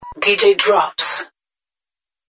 Get ready-made, professional DJ intros featuring your stage name created using cutting-edge AI vocals.
DJ NAME (Acapella Voice Only)